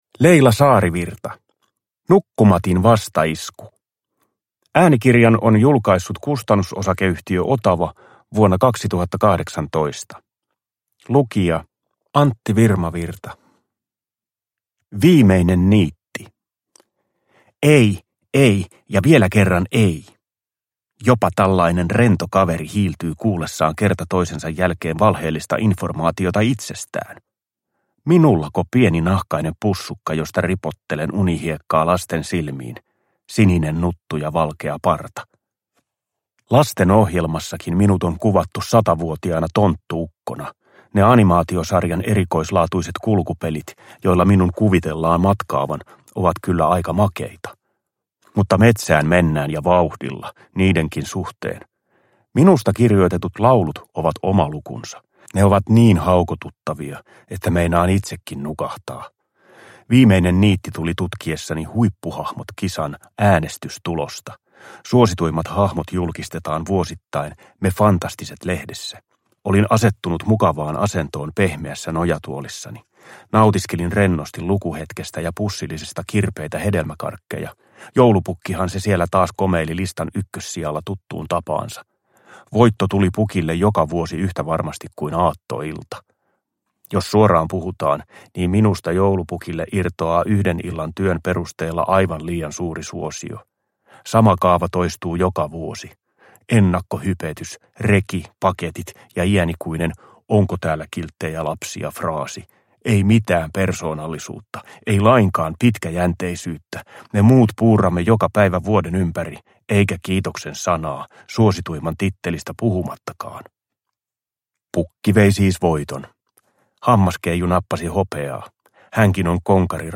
Nukkumatin vastaisku – Ljudbok – Laddas ner
Uppläsare: Antti Virmavirta